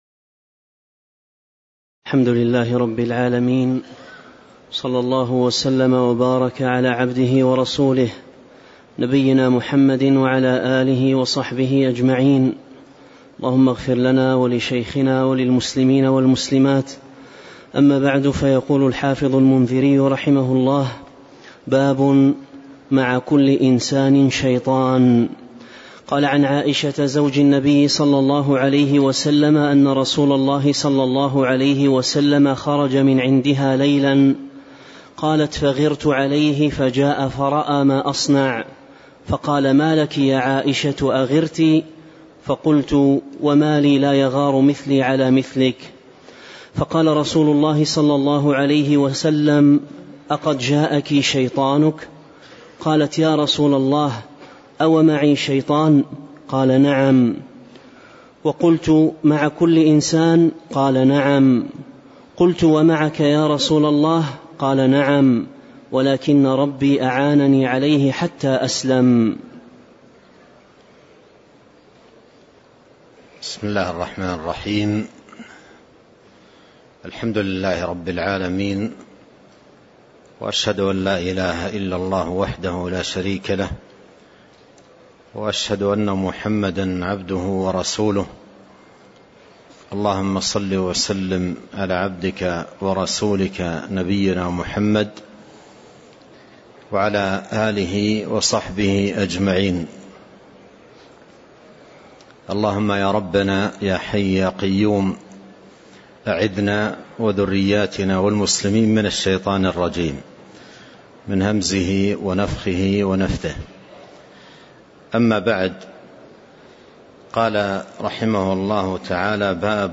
تاريخ النشر ١٧ ذو القعدة ١٤٤٣ هـ المكان: المسجد النبوي الشيخ